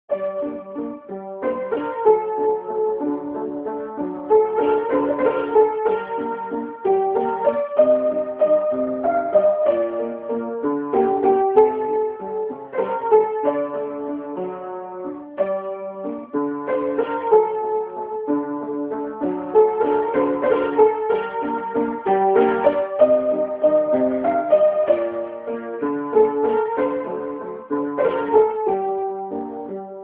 Романс «Осень настала» (мелодия любого из трёх его куплетов)